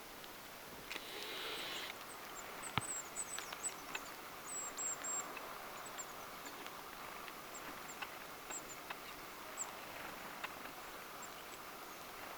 erikoinen ilmeisesti hömötiaislinnun ääni
erikoinen_ilm_homotiaislinnun_aani.mp3